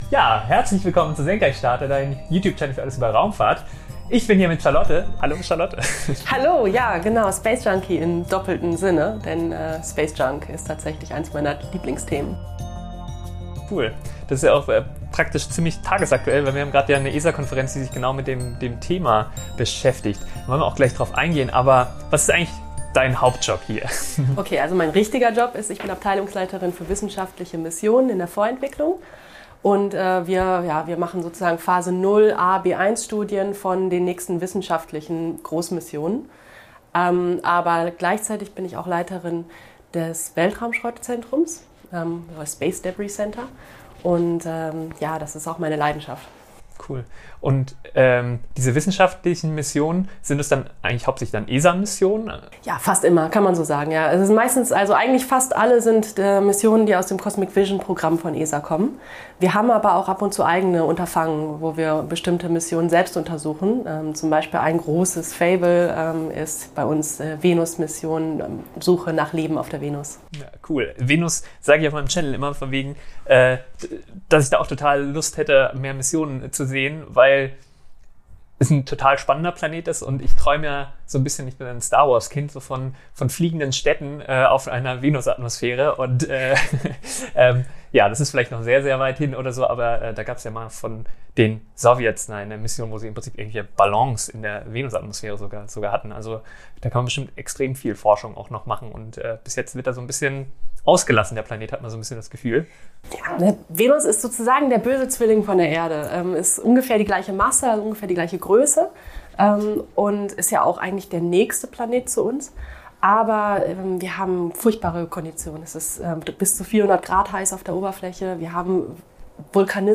Raumfahrt Interview ~ Senkrechtstarter Podcast